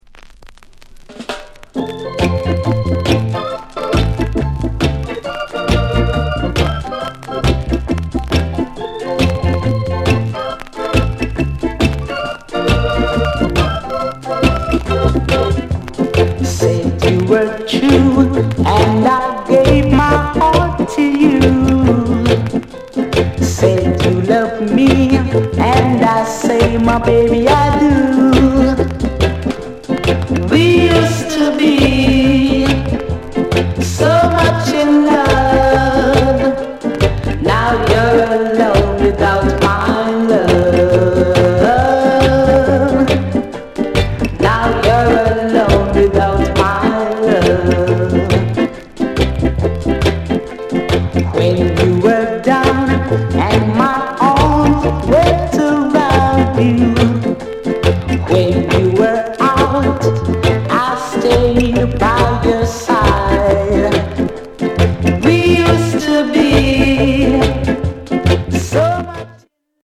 SOUND CONDITION A SIDE VG(OK)
SKINHEAD INST